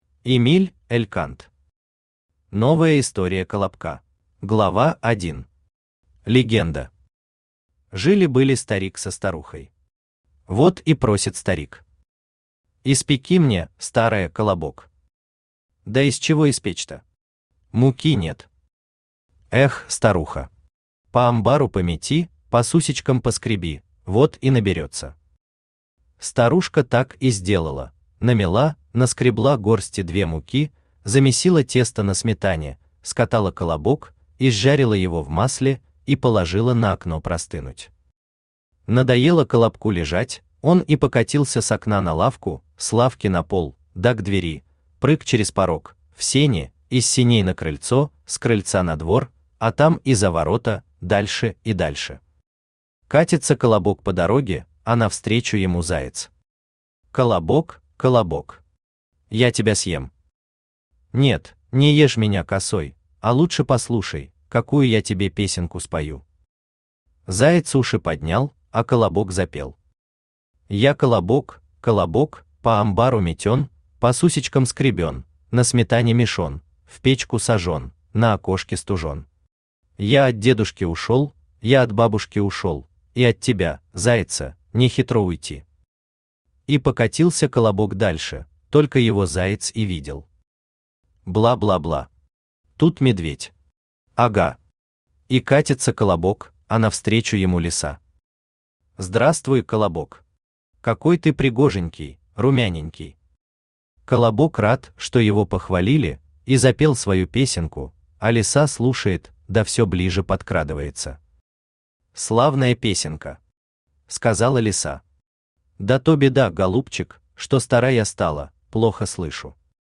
Аудиокнига Новая история Колобка | Библиотека аудиокниг
Aудиокнига Новая история Колобка Автор Эмиль Элькант Читает аудиокнигу Авточтец ЛитРес.